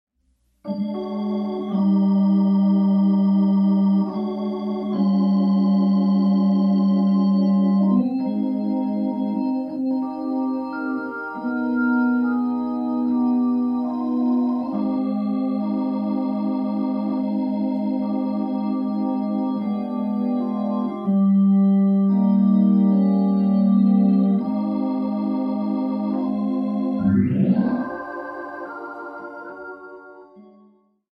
All FAT HEAD SAMPLES ARE RECORDED WITH A  STOCK TRANSFORMER
FAT HEAD Hammond B3: